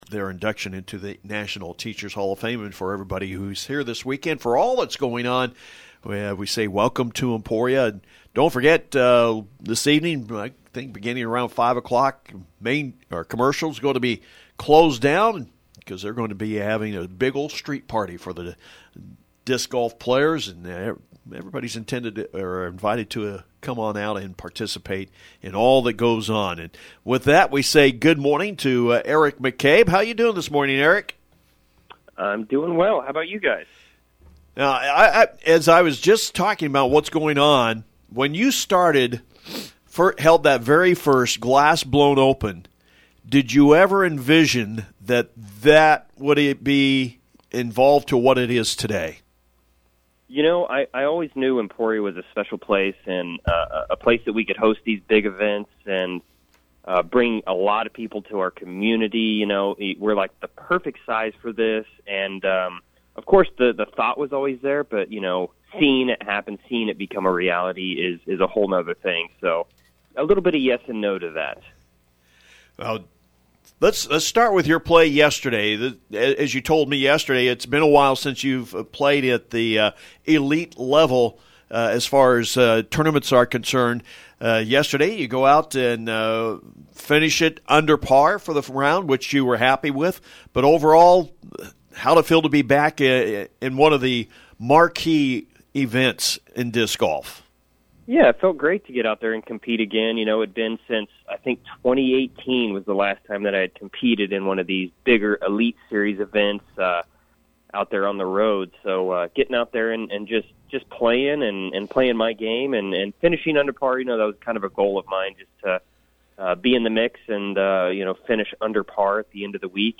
Sports Talk Guest